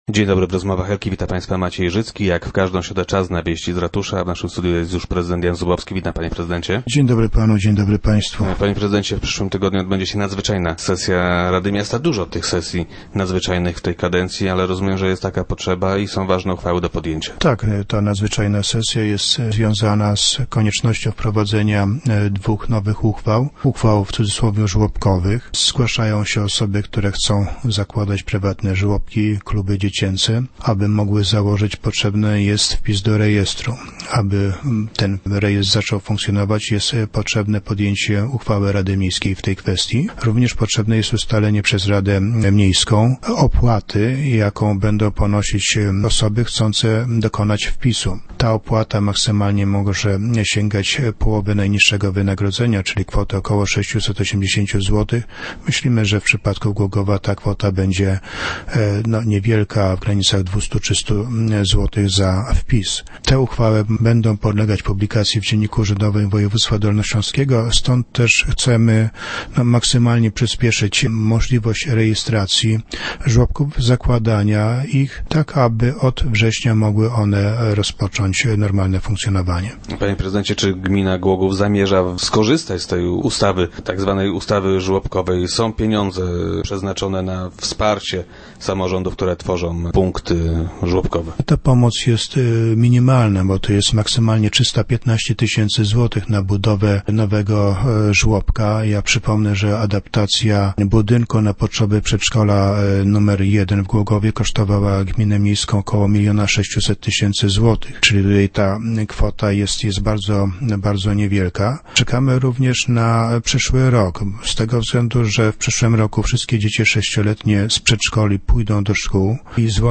Żeby jednak żłobki mogły w Głogowie powstawać, niezbędne są uchwały podjęte przez radnych. - Właśnie temu poświęcona będzie nadzwyczajna sesja rady, która odbędzie się tuż po świętach - mówił prezydent Jan Zubowski, który był dziś gościem Rozmów Elki.